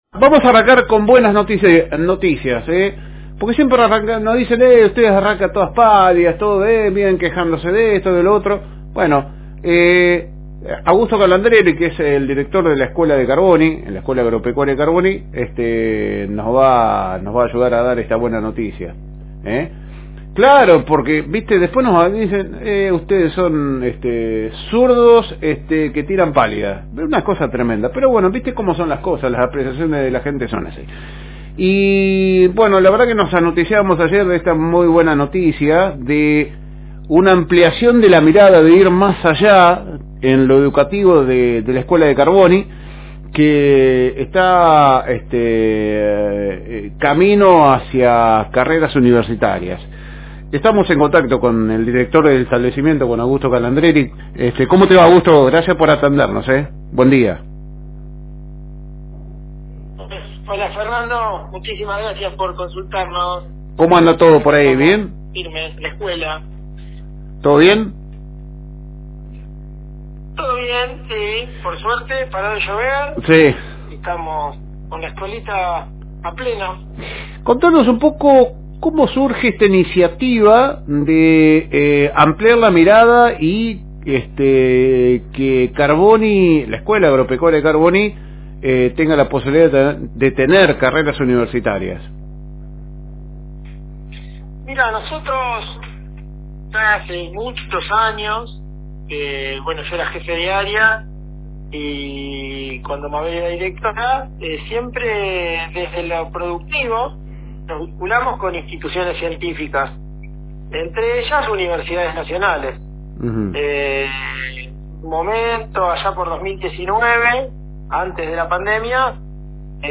En una reciente entrevista